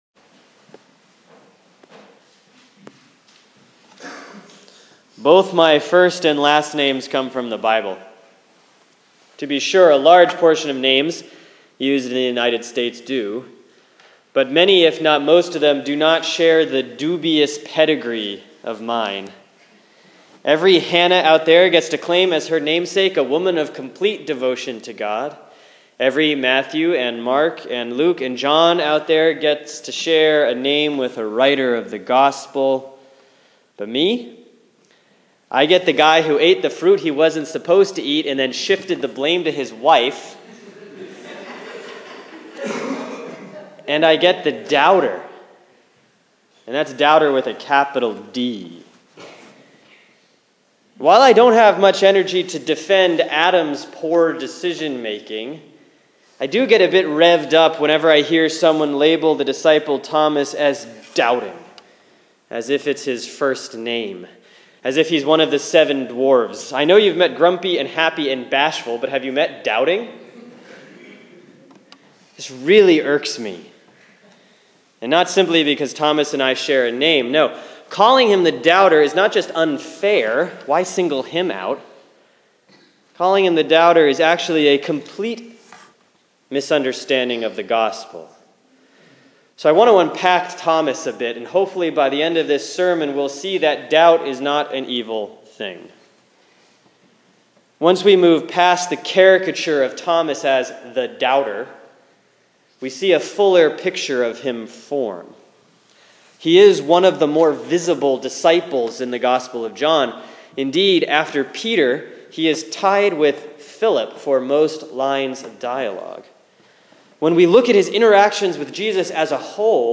Sermon for Sunday, April 3, 2016 || Easter 2C || John 20:19-31